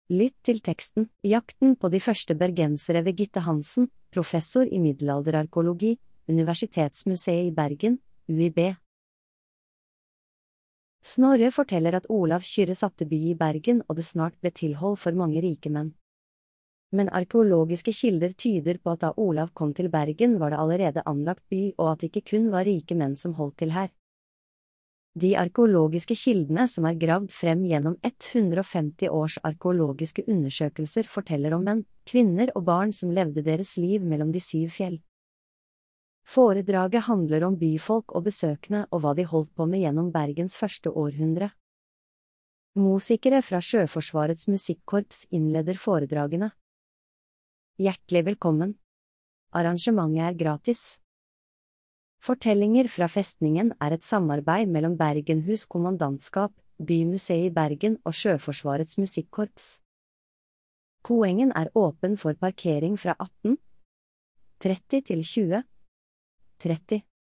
Fortellinger fra Festningen er historiske foredrag. t samarbeid mellom Bergenhus Kommandantskap, Bymuseet i Bergen og Sjøforsvarets musikkorps.